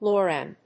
音節lo・ran 発音記号・読み方
/lˈɔːræn(米国英語), ˈlɔ:ˌræn(英国英語)/